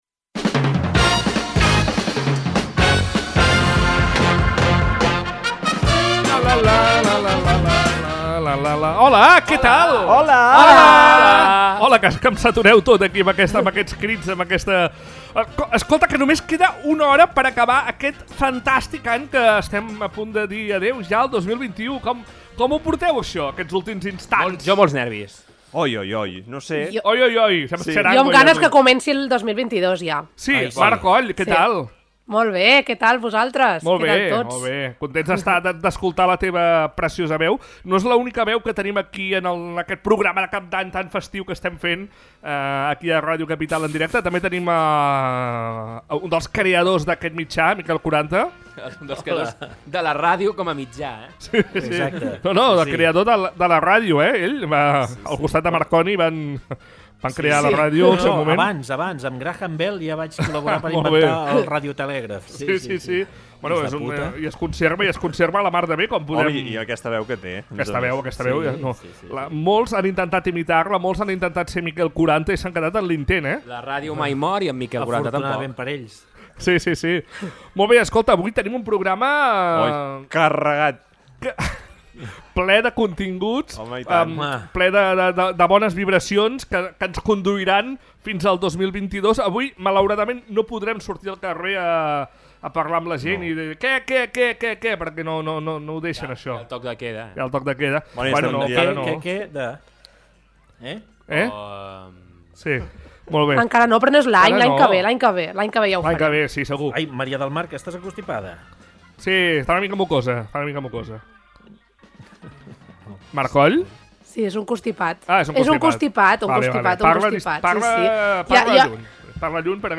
Hem escoltat música, repassat fails i hem fet les campanades en directe!
Un programa on hem escoltat les cançons de l’any per l’equip de l’emissora, hem recuperat els fails viscuts durant la temporada i hem fet en directe (amb gallines incloses) les campanades.